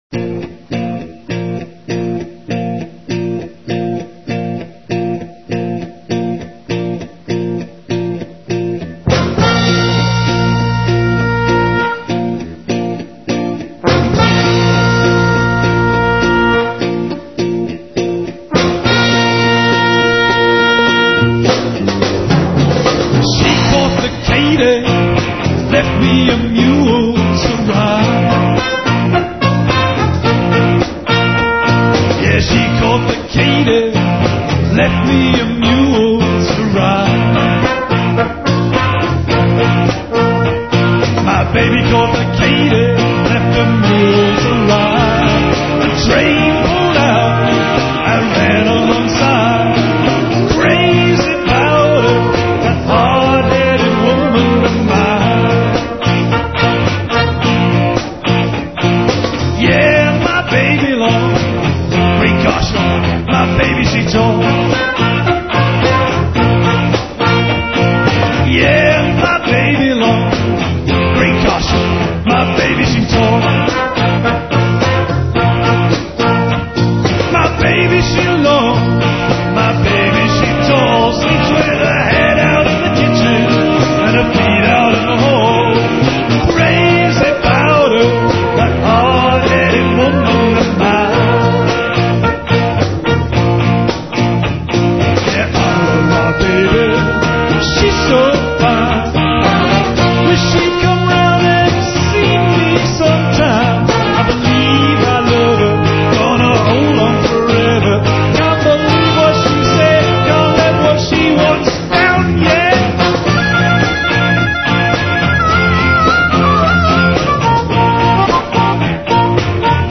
Recorded on a cold Saturday morning, with 12 hours notice.
and harmonica.
trumpet
Recorded in MP3 format, Mono, 11kHz.